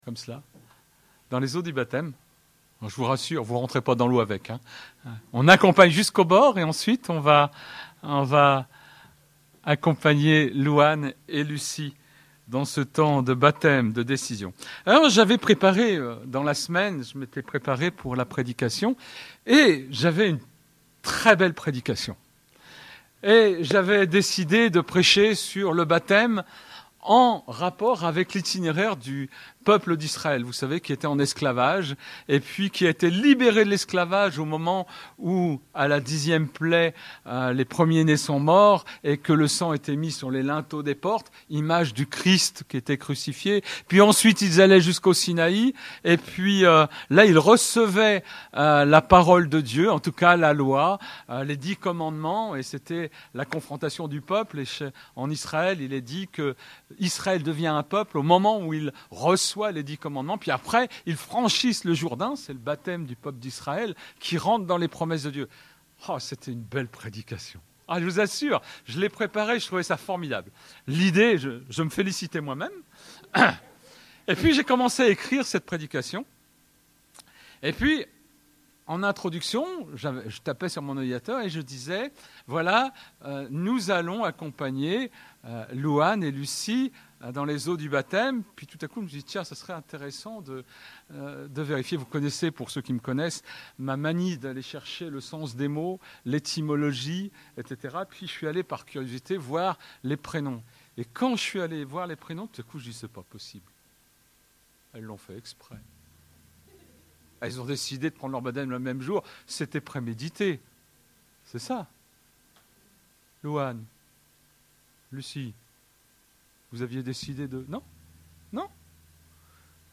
Prédication des baptêmes